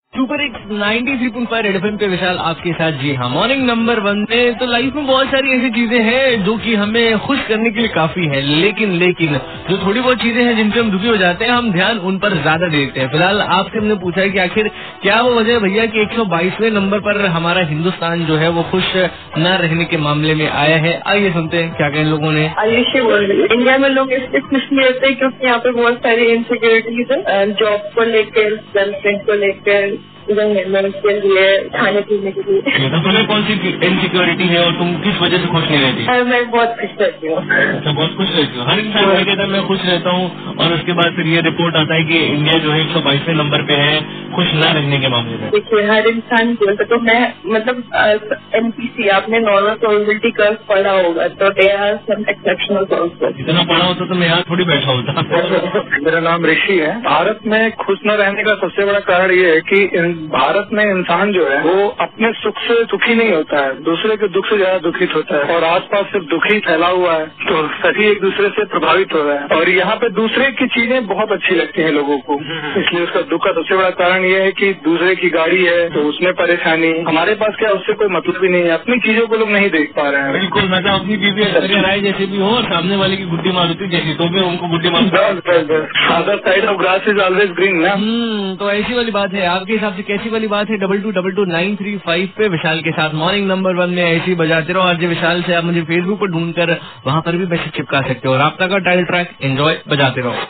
CALLERS BYTE ABOUT SURVEY